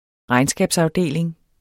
regnskabsafdeling substantiv, fælleskøn Bøjning -en, -er, -erne Udtale Betydninger afdeling i en virksomhed eller organisation der varetager ind- og udbetalinger og udarbejder regnskaber mv.